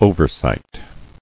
[o·ver·sight]